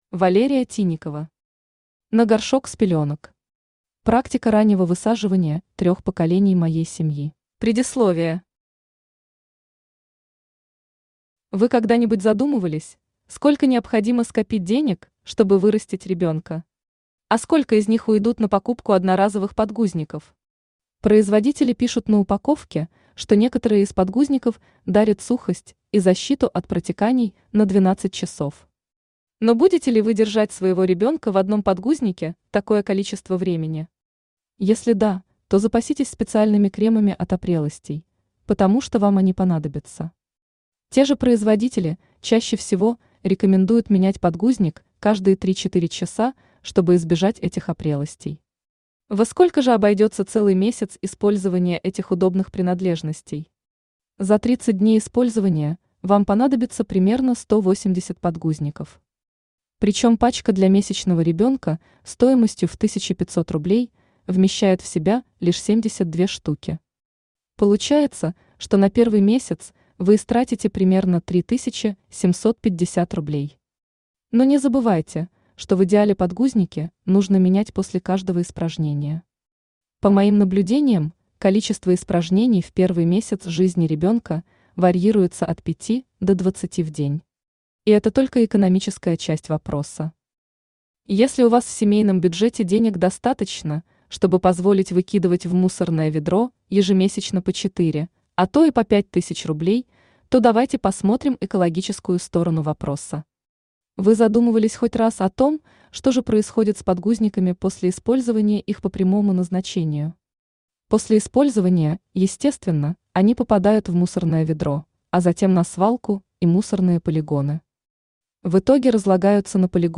Практика раннего высаживания трёх поколений моей семьи Автор Валерия Тинникова Читает аудиокнигу Авточтец ЛитРес.